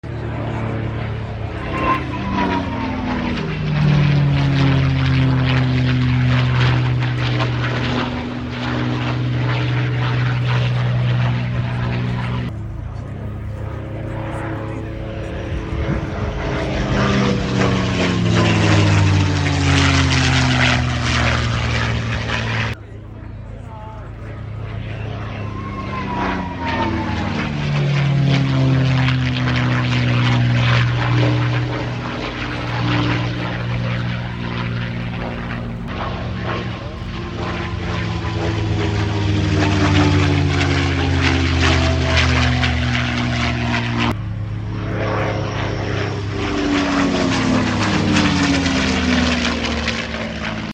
P51 Display At The Headcorn Sound Effects Free Download